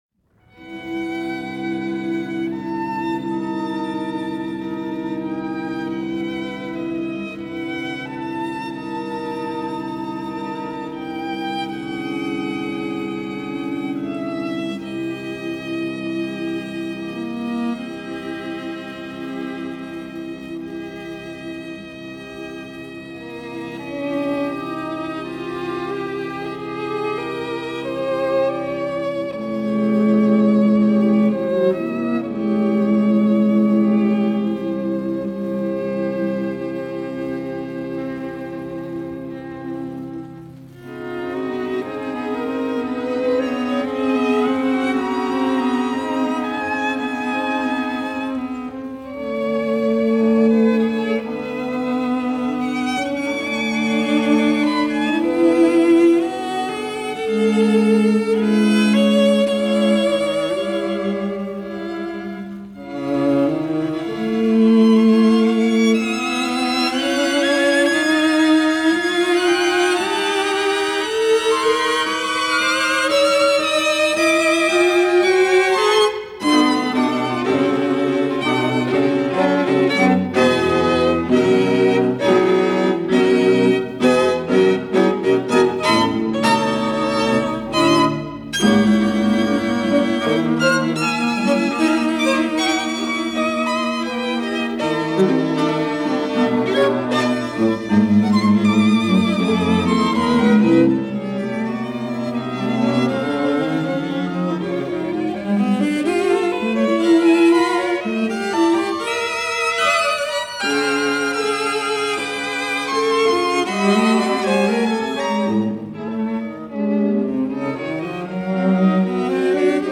This is the premier recording of that revised version.
first violin
second violin
viola
cello.